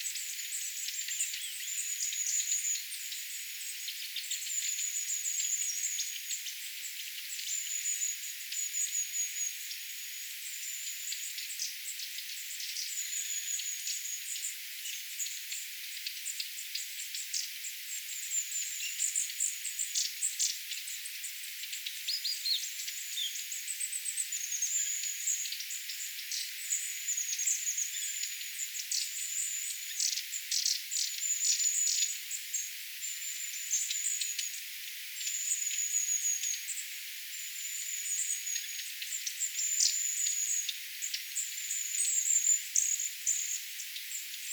käsittääkseni pyrstötiaisen
joitakin kuin tiaismaisia ääniä
kasittaakseni_pyrstotiaisen_joitakin_tiaismaisia_aantelyja.mp3